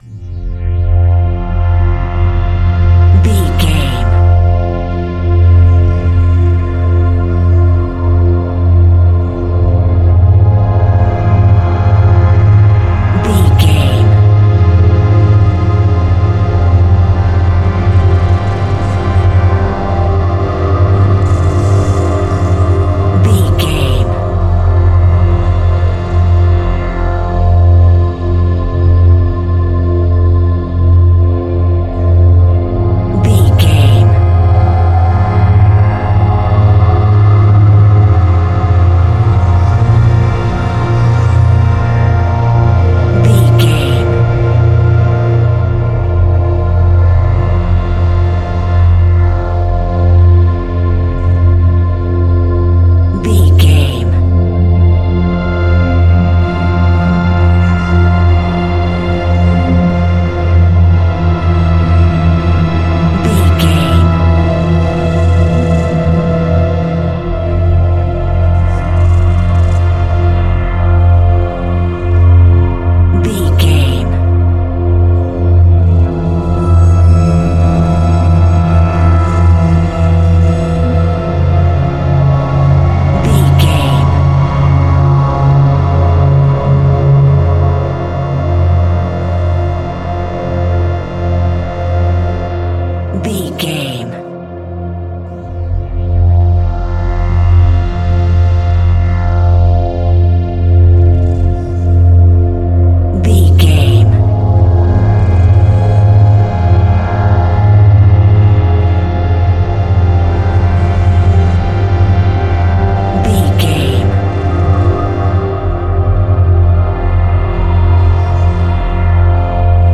Aeolian/Minor
Slow
tension
ominous
dark
haunting
eerie
synthesiser
strings
Synth Pads
atmospheres